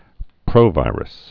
(prōvīrəs, prō-vī-)